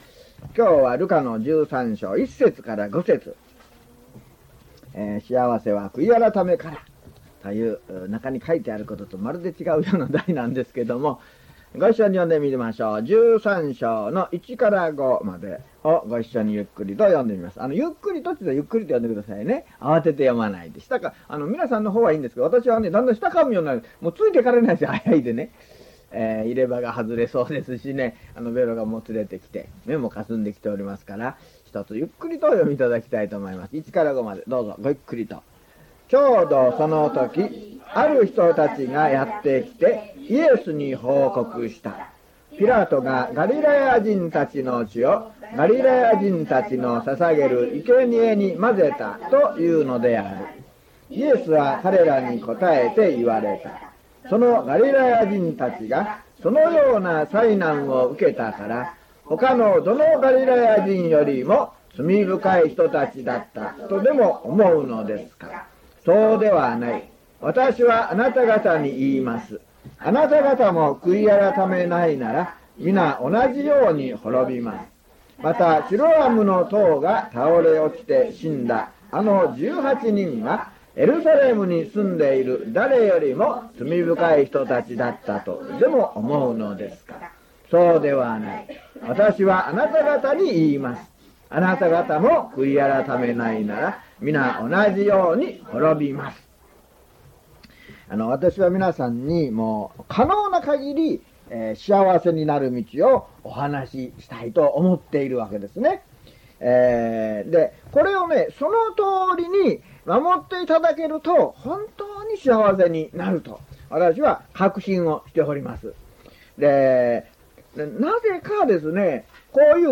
luke097mono.mp3